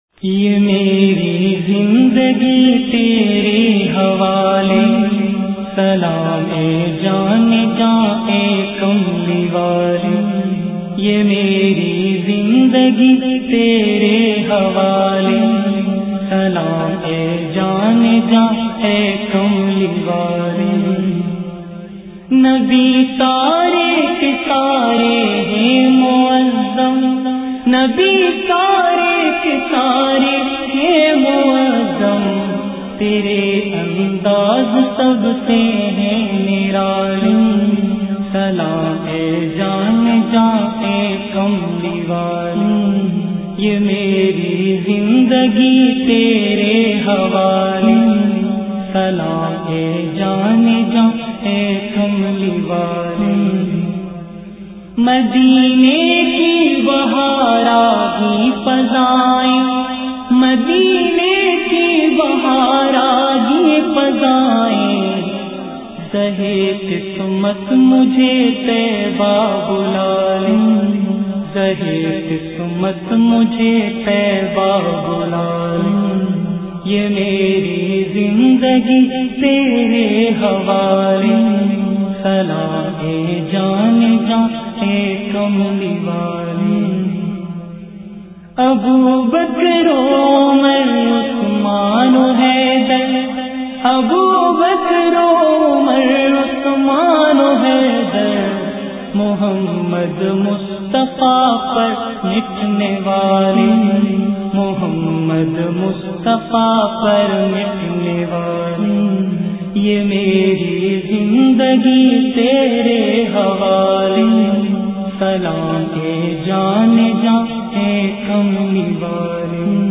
Naats